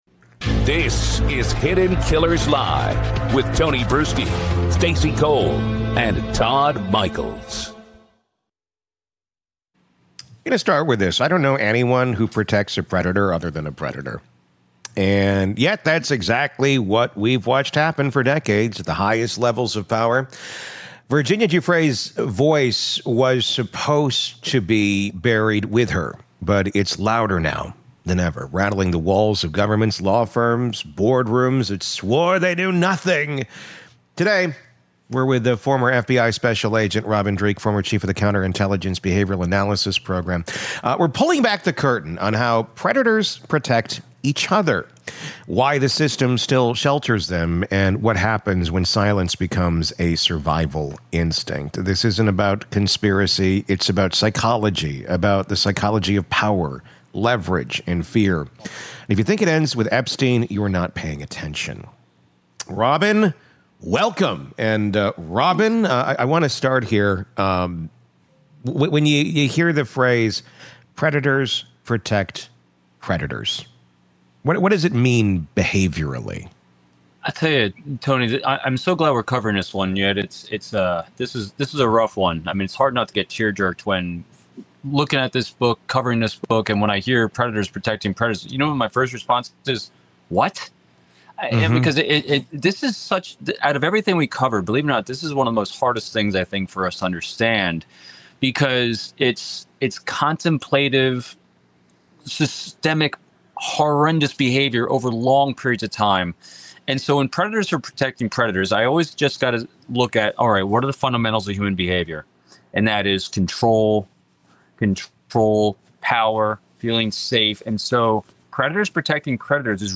This conversation pulls no punches: the FBI’s locked Epstein files, the normalization of abuse within elite circles, the weaponization of bureaucracy, and the spiritual rot that comes when morality becomes negotiable.